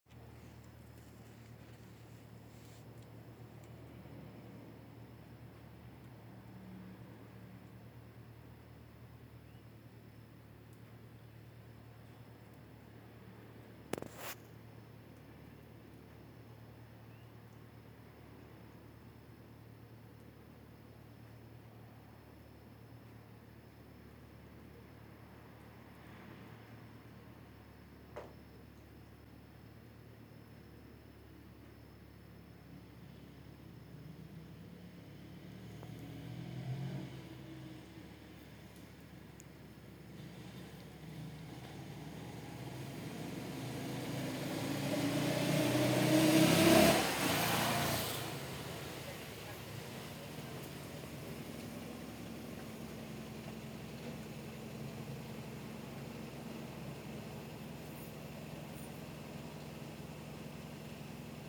El sonido del silencio HEREDIA